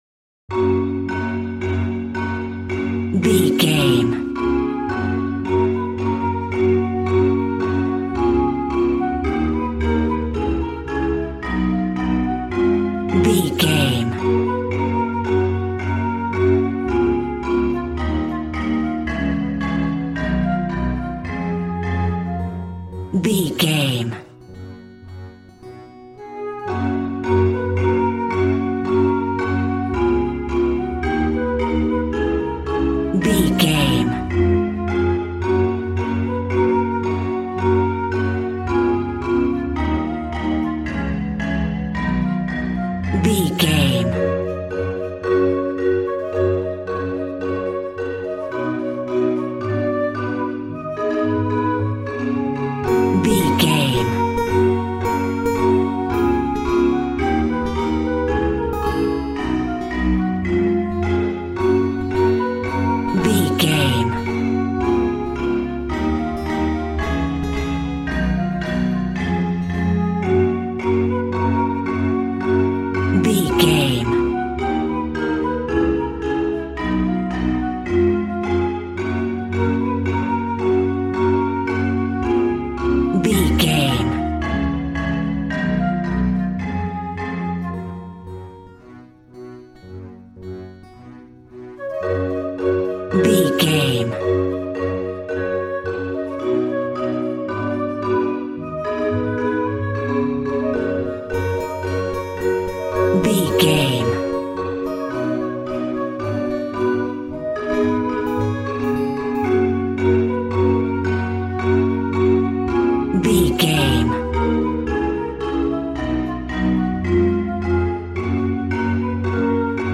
Ionian/Major
G♭
positive
cheerful/happy
joyful
drums
acoustic guitar